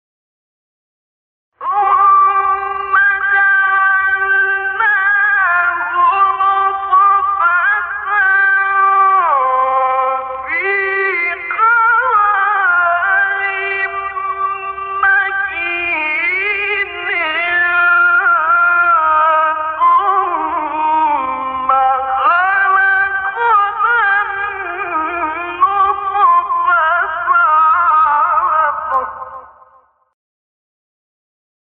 سایت-قرآن-کلام-نورانی-نهاوند-شعیشع-1.mp3